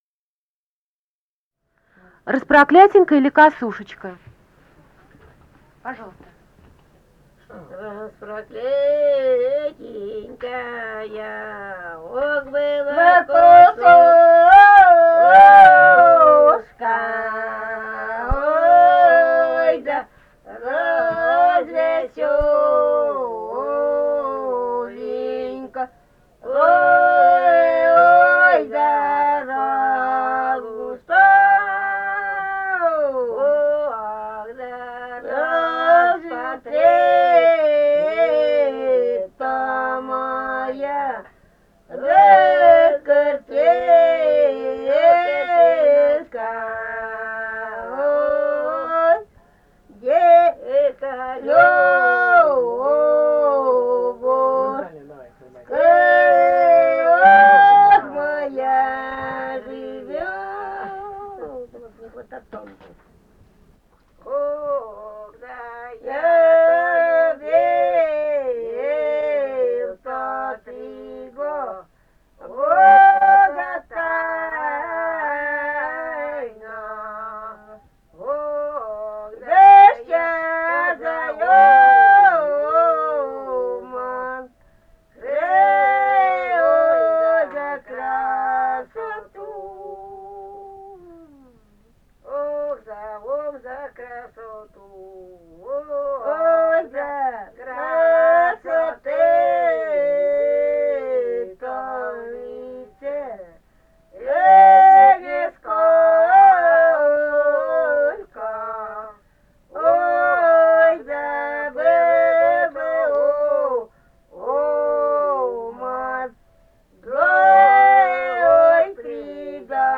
Этномузыкологические исследования и полевые материалы
Пермский край, д. Берёзовка Кунгурского района, 1968 г. И1079-19